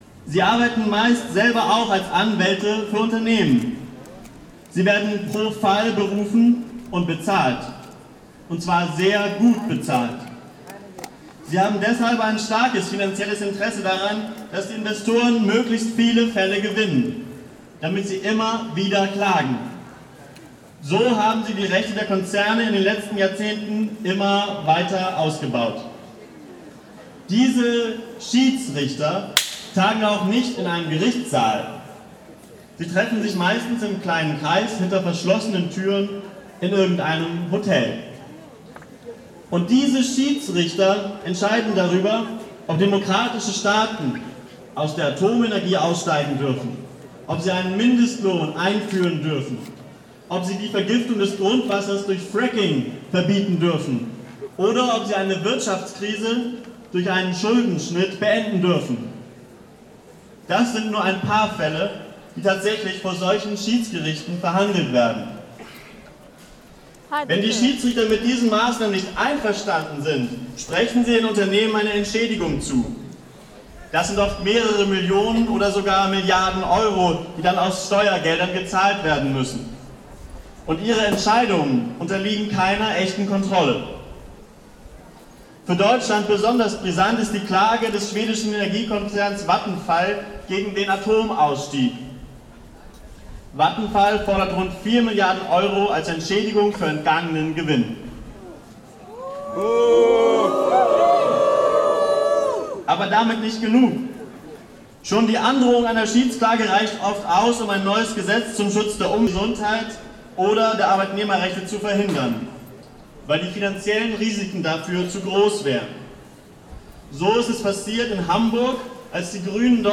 Aktionstag gegen TTIP Freiburg 11.10.2014
Reden wurden gehalten und  Unterschriften unter die selbstorganisierte Europäische Bürgerinitiatvie gesammelt.
Redebeiträge vom Auftakt: